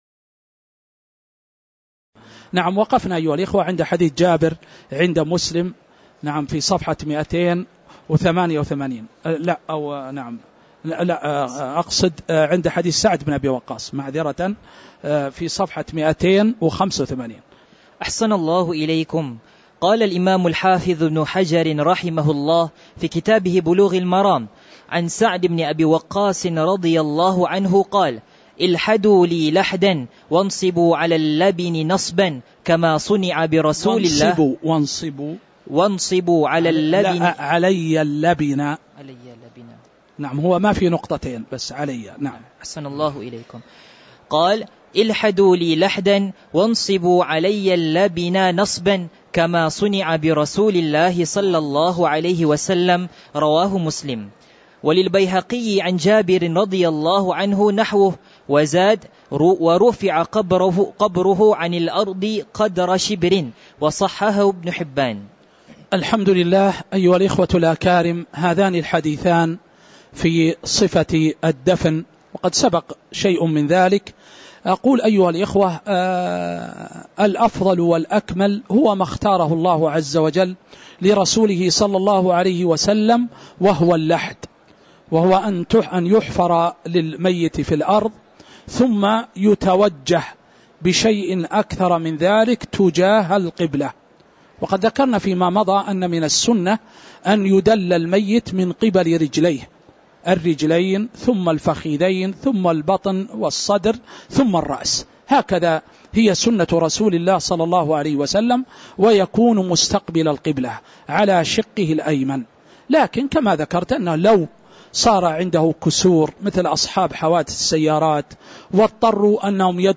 تاريخ النشر ١٩ شعبان ١٤٤٥ هـ المكان: المسجد النبوي الشيخ